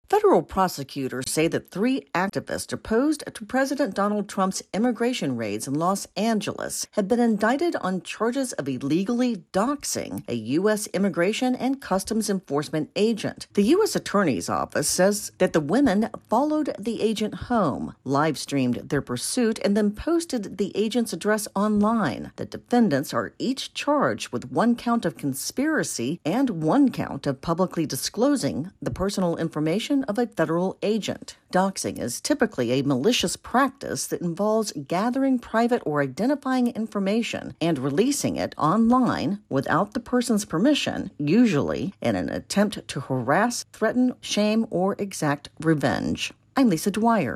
reports on 3 activists now charged with doxing an ICE agent in Los Angeles.